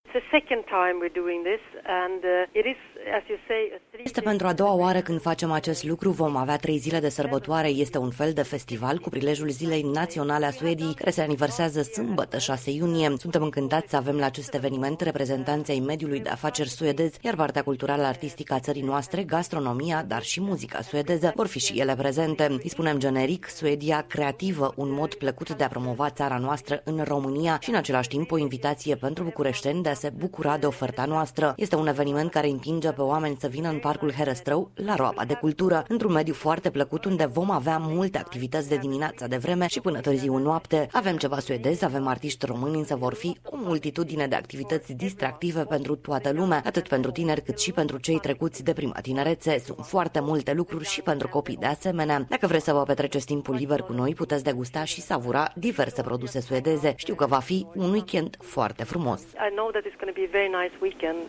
Ambasador Anne Lindahl Kenny: